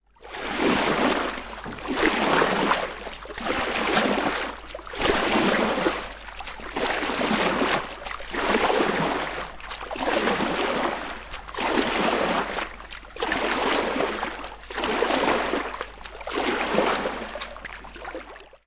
На этой странице собраны звуки весла: плеск воды, ритмичные гребки и другие умиротворяющие аудиоэффекты.
Плывем по речным волнам